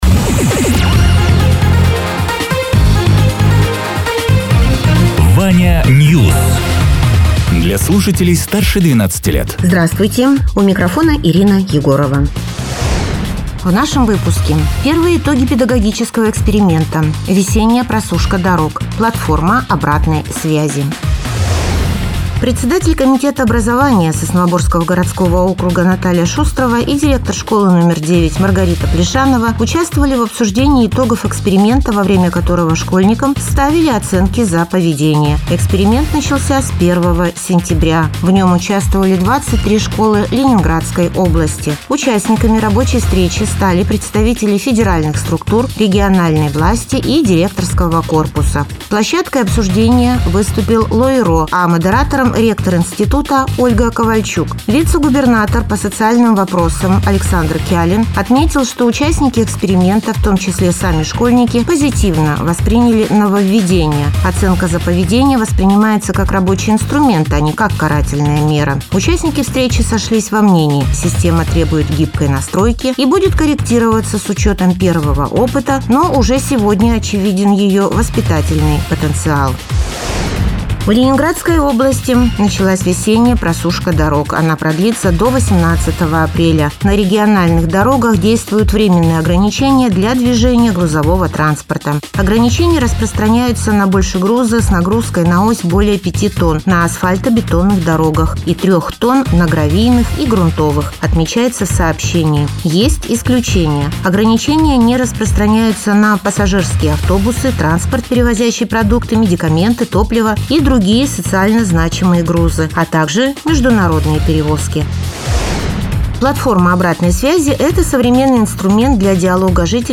Радио ТЕРА 25.03.2026_10.00_Новости_Соснового_Бора